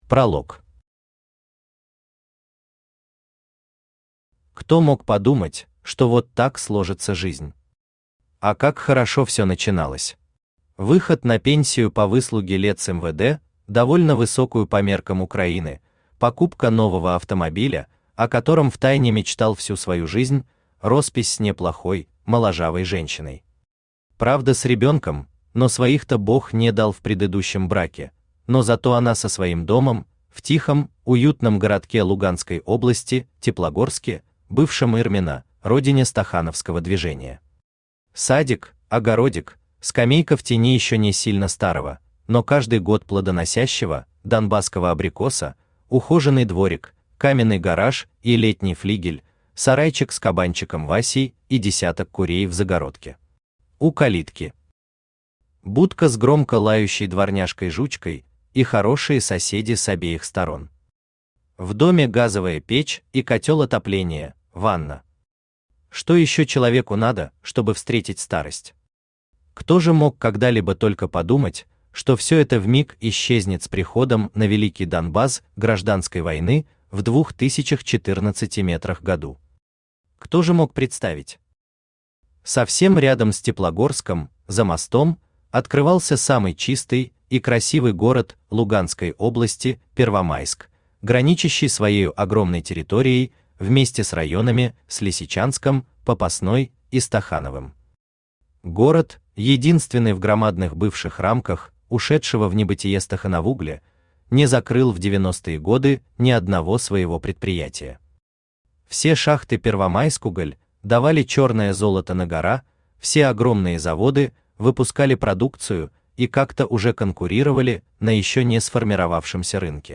Аудиокнига Опер, или ВОРы и ВоРЫ | Библиотека аудиокниг
Aудиокнига Опер, или ВОРы и ВоРЫ Автор Игорь Анатольевич Шпотенко Читает аудиокнигу Авточтец ЛитРес.